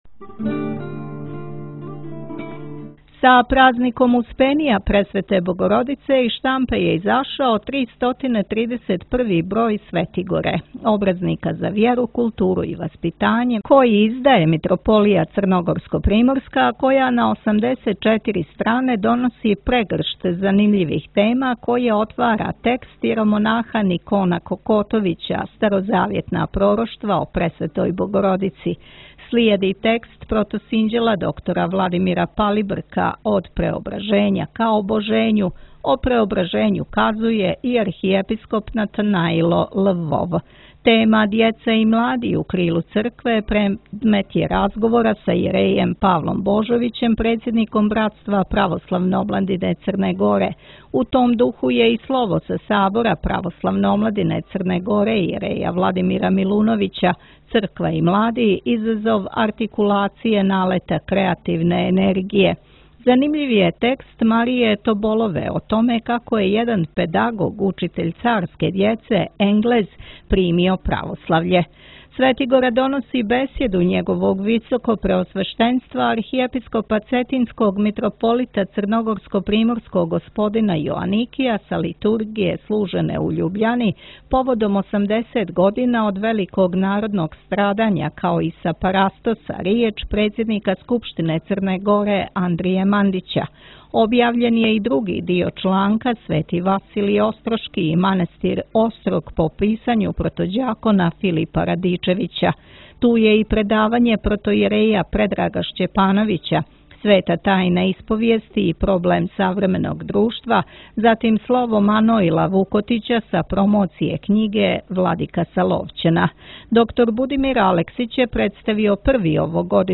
Предавање